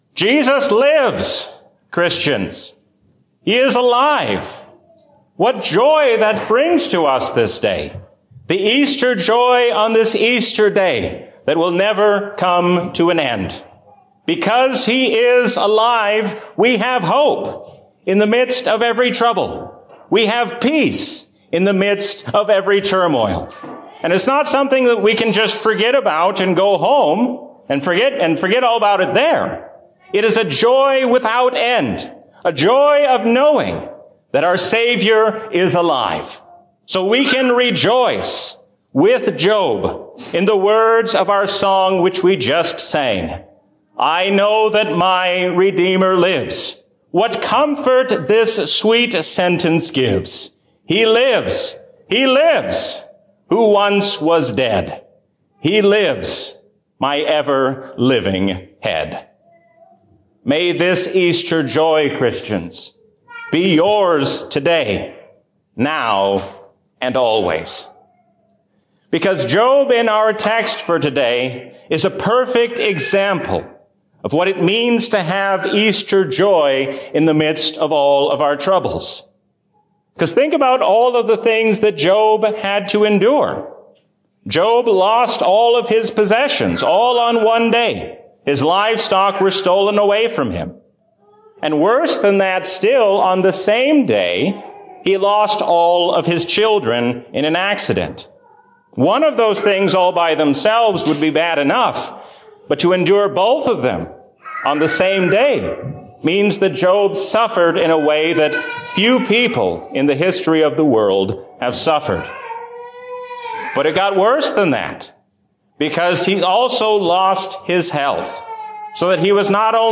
A sermon from the season "Easter 2021." We can rejoice because the Lord tells us how all things will end in His victory.